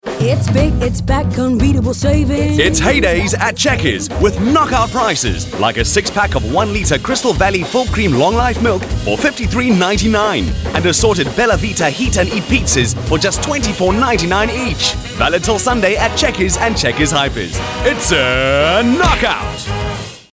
South Africa
agile, brisk, fast-paced, rapid
My demo reels